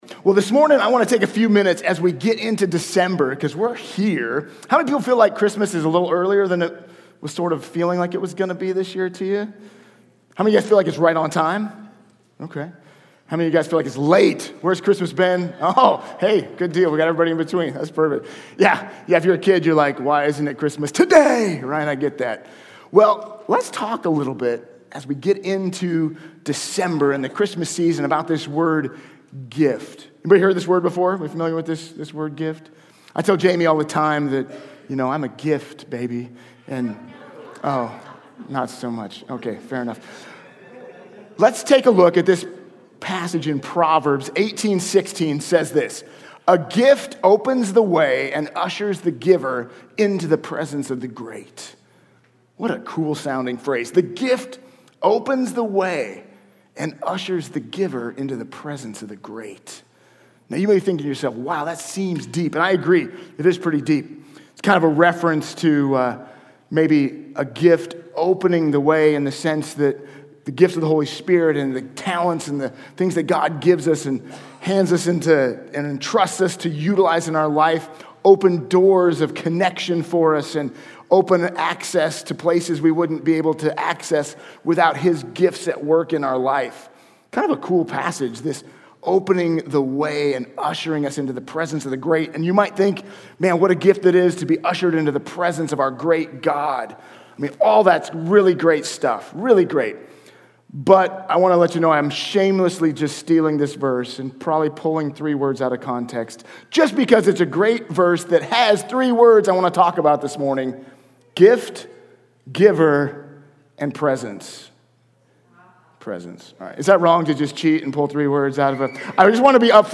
Sermons | White Rock Fellowship